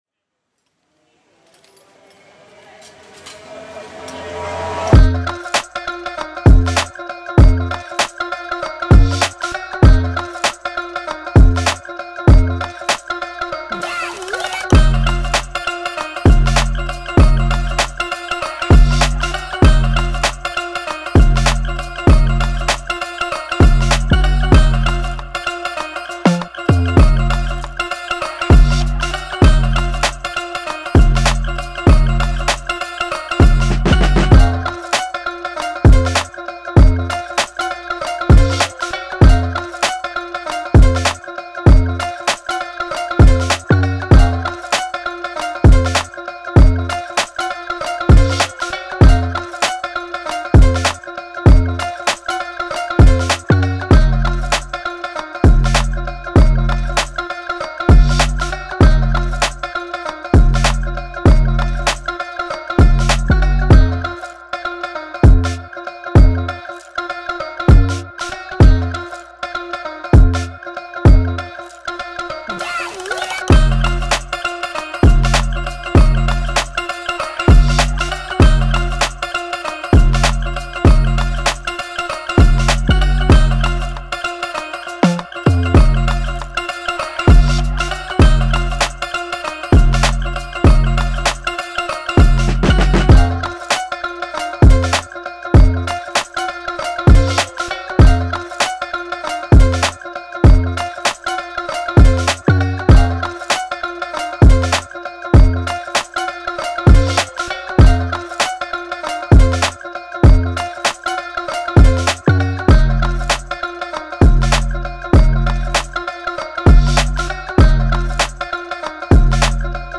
Rap
fMinor
Dark, Moody, Villainous